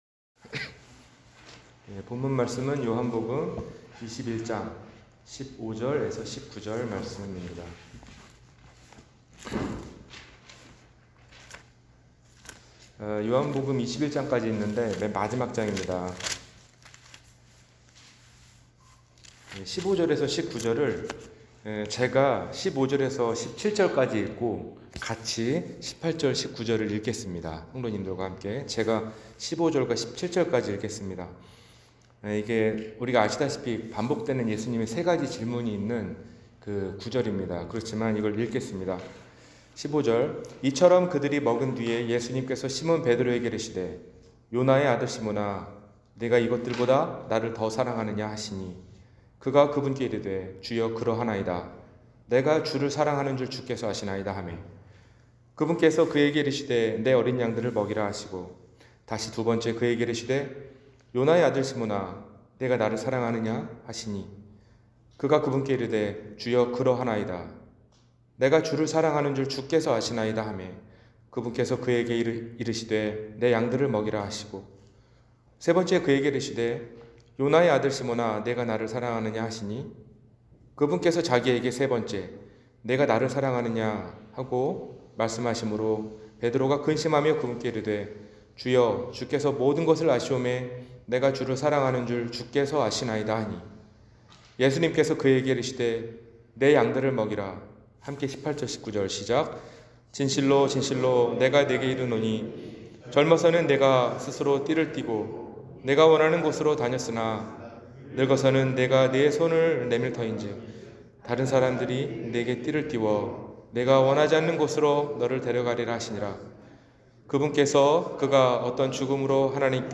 네가 나를 사랑하느냐-주일설교